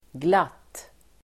Uttal: [glat:]